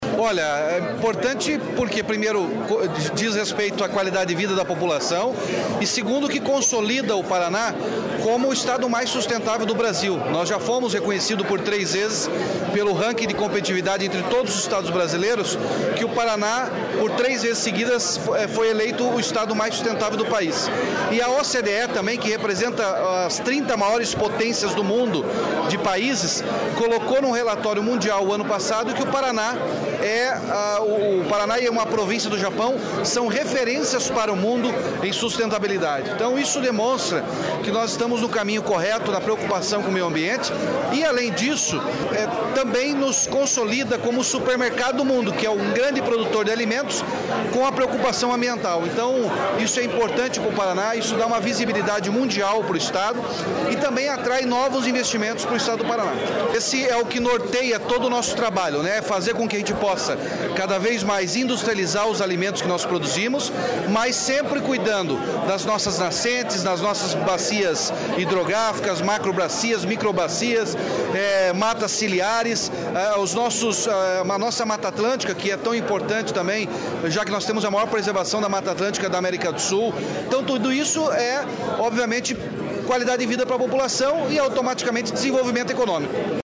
Sonora do governador Ratinho Junior sobre a agricultura sustentável no Estado